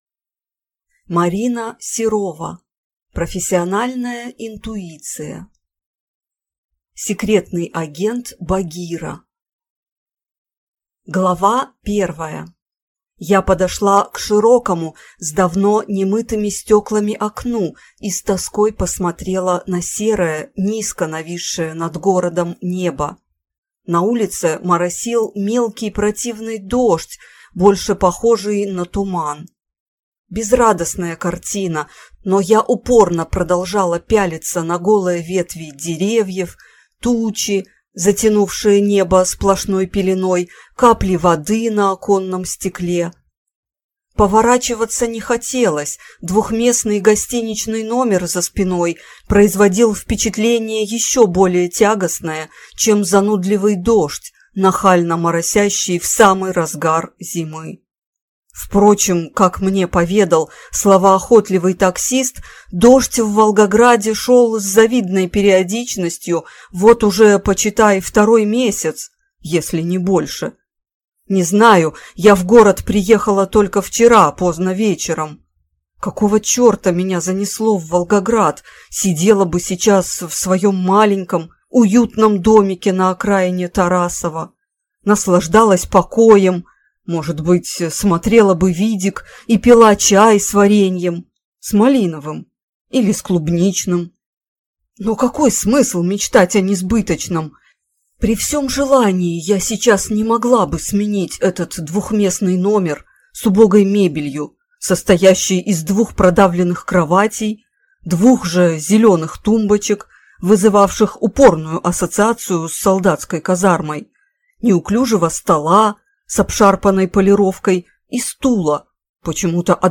Аудиокнига Профессиональная интуиция | Библиотека аудиокниг